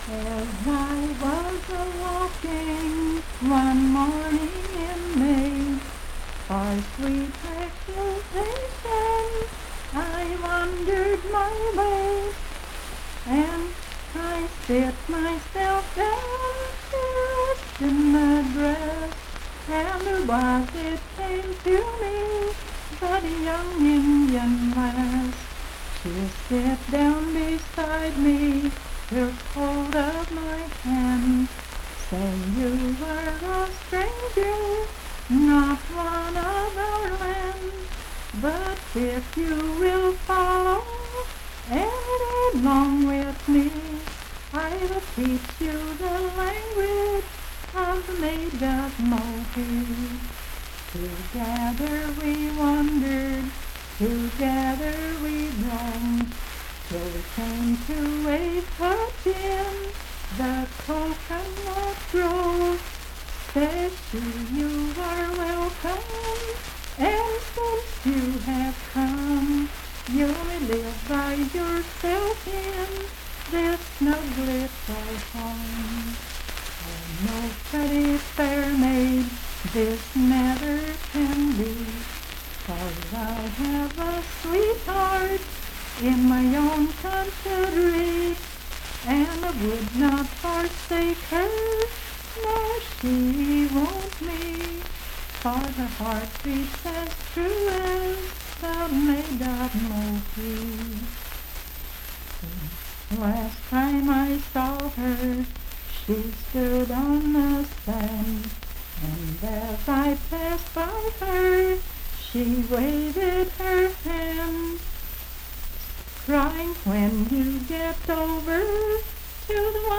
Unaccompanied vocal music
in Laurel Dale, W.V.
Voice (sung)